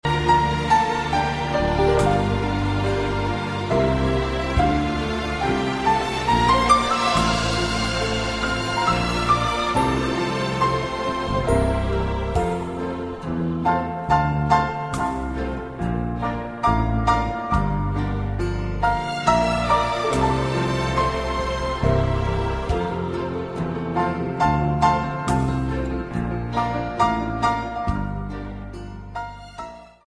Categoria Classiche